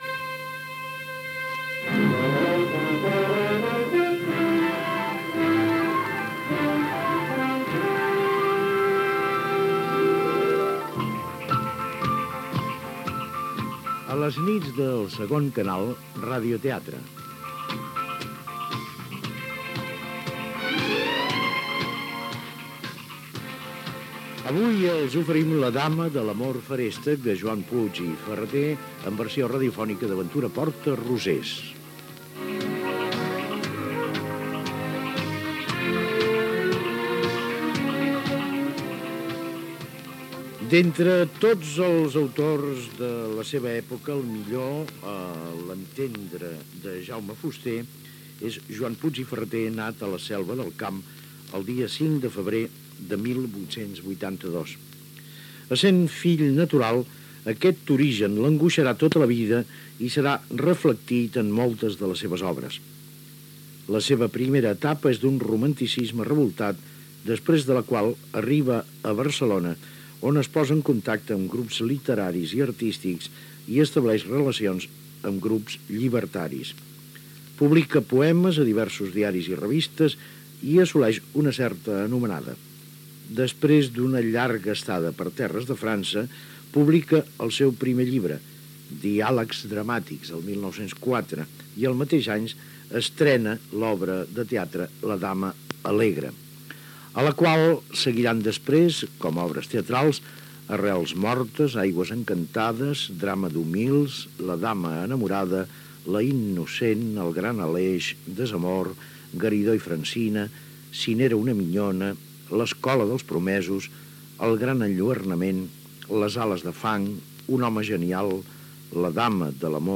Gènere radiofònic Ficció